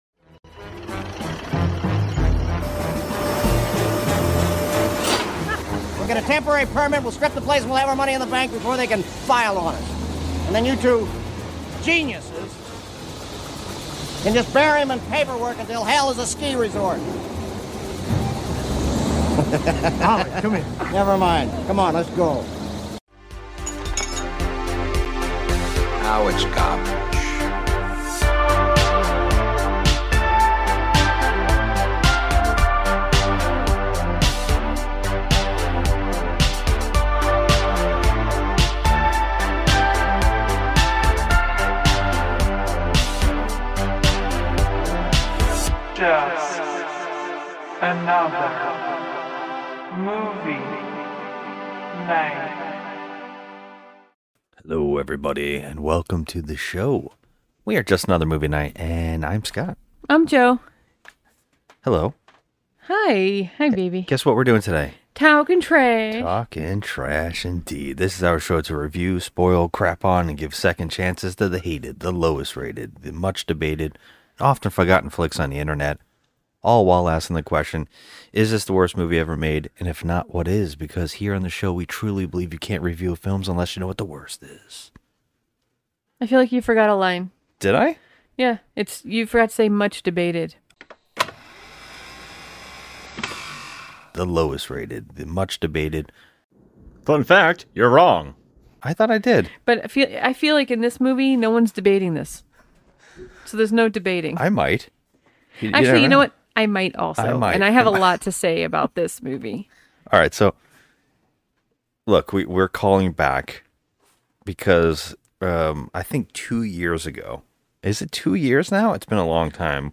Finally we decided to get off our lazy Asses and start recording our nightly rants, reviews and conversations on all things entertainment (but mainly horror movies).